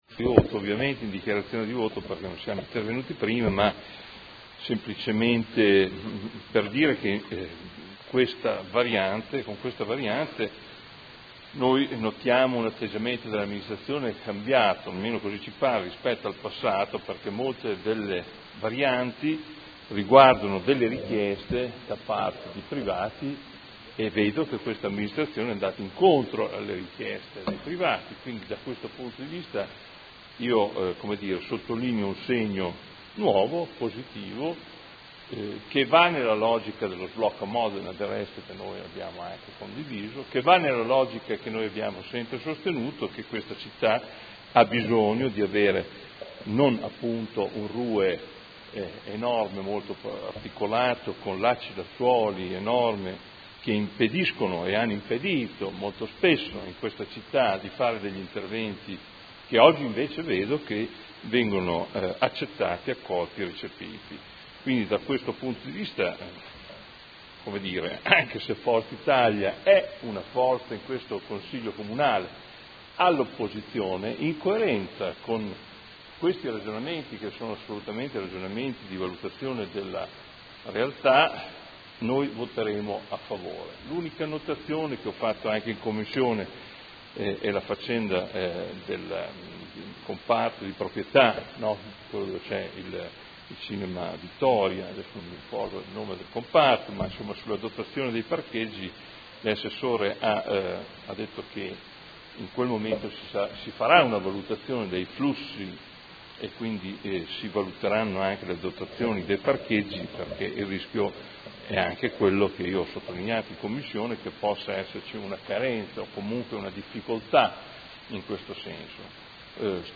Seduta del 22 ottobre. Proposta di deliberazione: Variante al Piano operativo comunale (POC) e al Regolamento urbanistico edilizio (RUE) – Controdeduzioni e approvazione ai sensi degli artt 33 e 34 della Legge regionale 20/2000 e s.m. Dichiarazione di voto